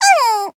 文件 文件历史 文件用途 全域文件用途 Chorong_dmg_03.ogg （Ogg Vorbis声音文件，长度0.5秒，155 kbps，文件大小：10 KB） 源地址:地下城与勇士游戏语音 文件历史 点击某个日期/时间查看对应时刻的文件。